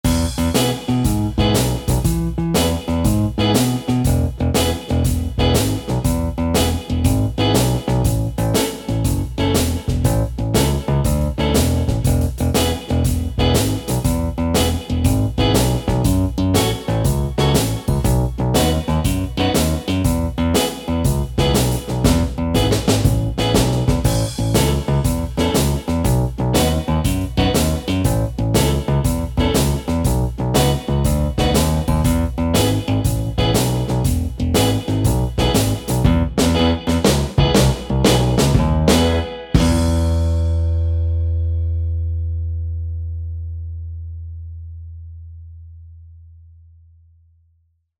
Manytone Acoustic Electric Bass
Includes Multi-velocity full length samples as well as Harmonics, Mute notes, Slides and other FX.
manytone_ovacoustic_bass_guitar_demo3.mp3